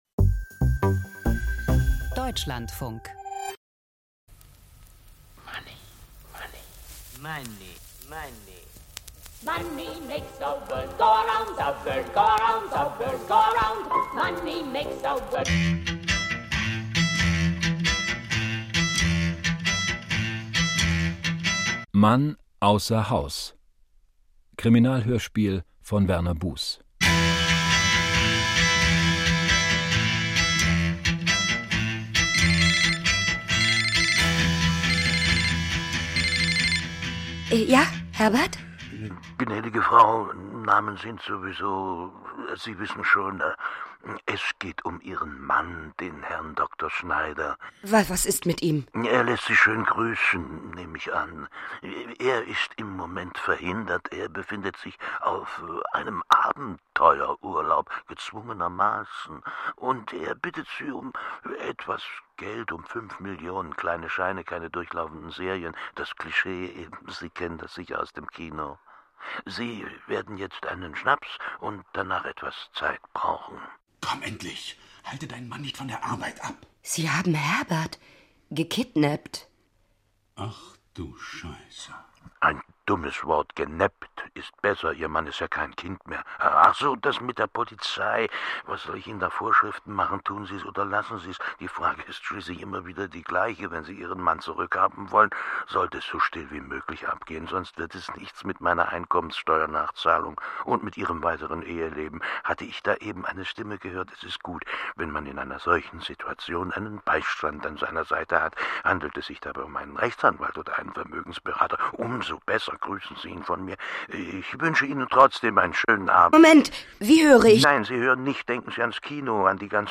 Krimi-Hörspiel: Eine mörderische Patientin - Zerbrochene Flügel